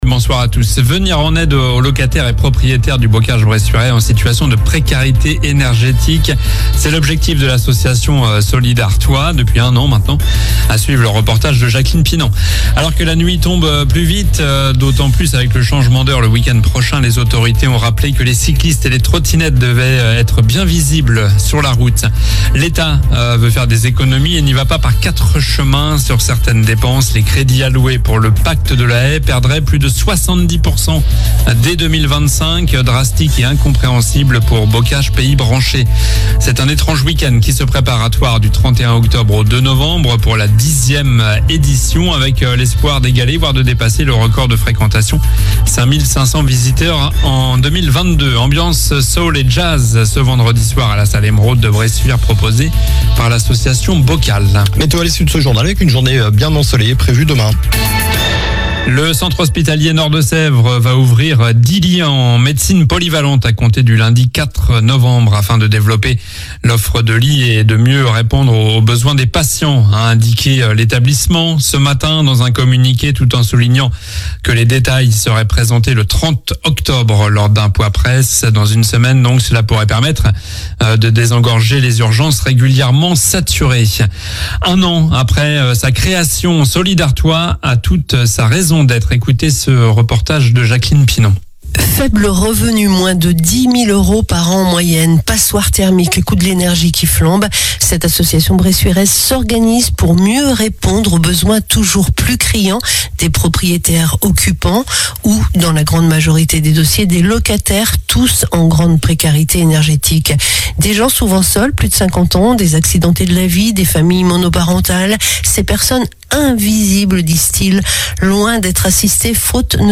Journal du mardi 22 octobre (midi)